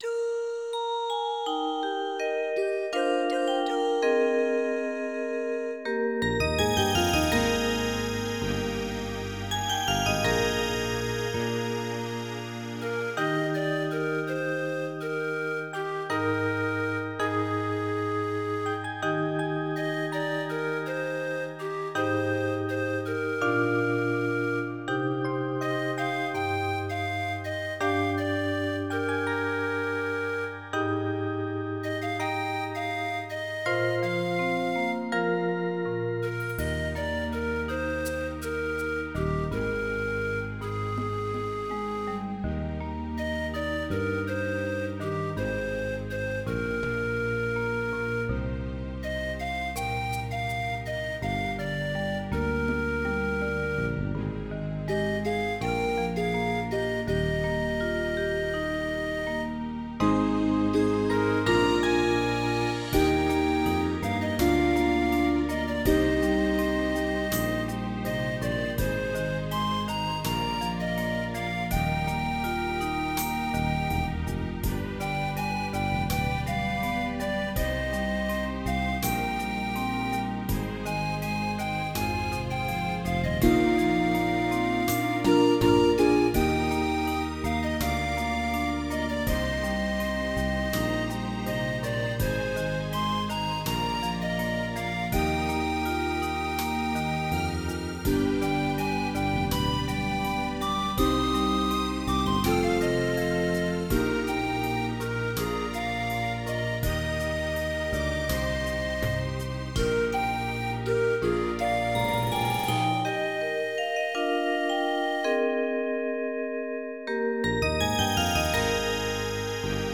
General MIDI